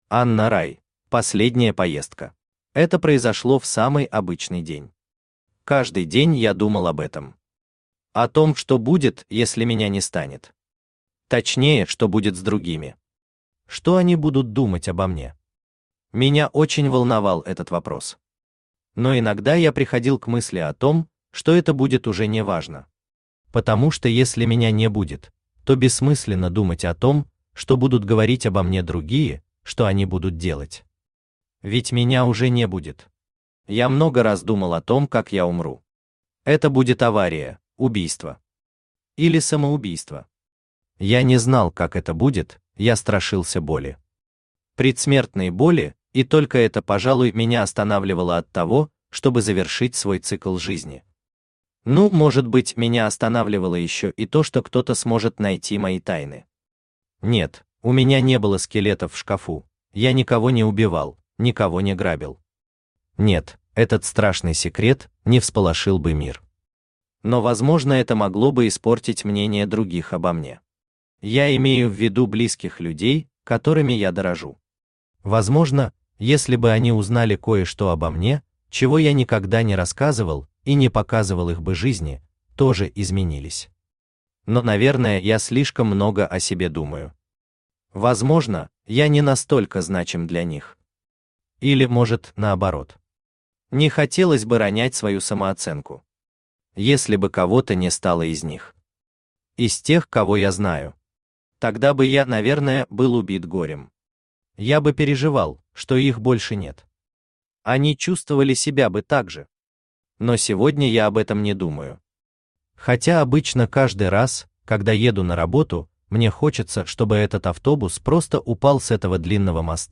Аудиокнига Последняя поездка | Библиотека аудиокниг
Aудиокнига Последняя поездка Автор Смотрящий С.М. Читает аудиокнигу Авточтец ЛитРес.